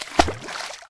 splash.ogg